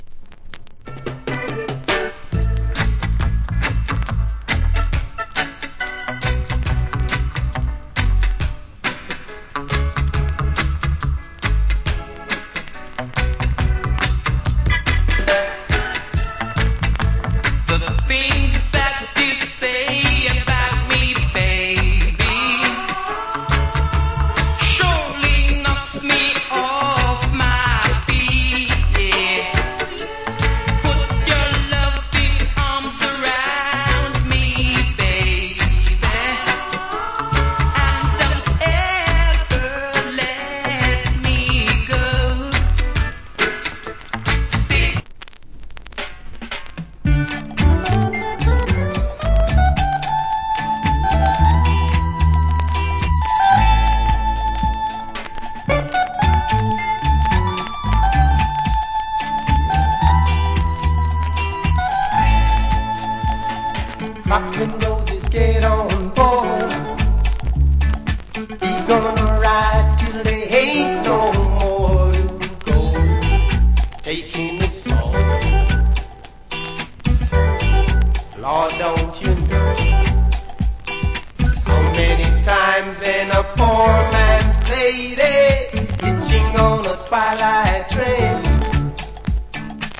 Cool Reggae Vocal.